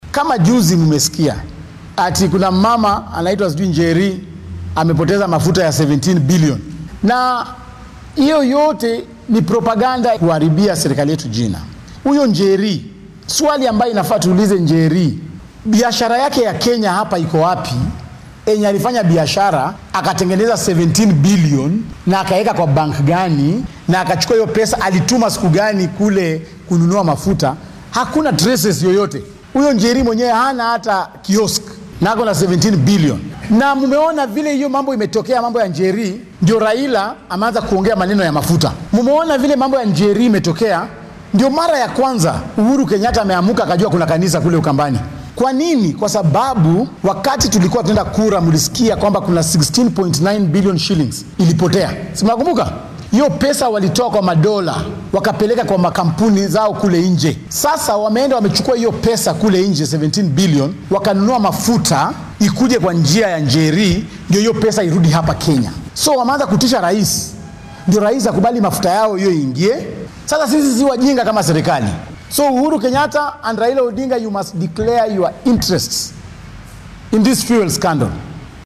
Malala ayaa arrimahan ka hadlay xilli uu shir jaraaid qabtay in kastoo uunan caddeymo soo bandhigin.